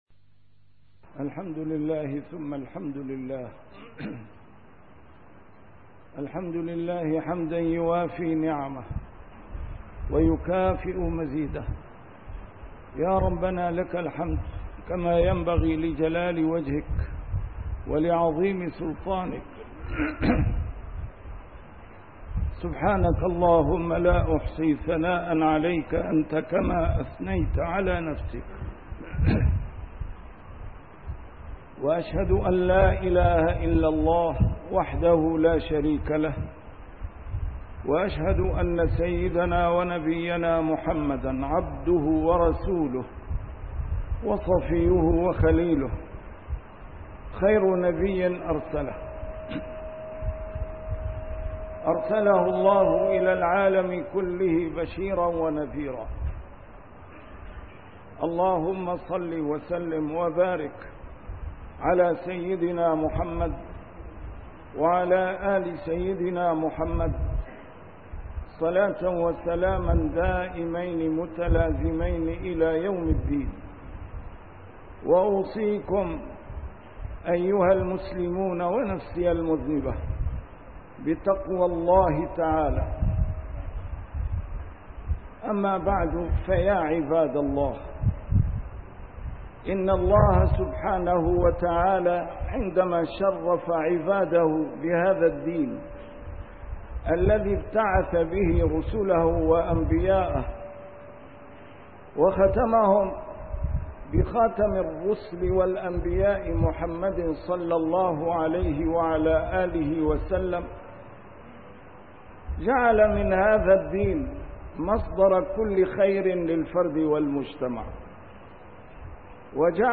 A MARTYR SCHOLAR: IMAM MUHAMMAD SAEED RAMADAN AL-BOUTI - الخطب - إلى المفتونين بأخلاق المجتمعات الغربية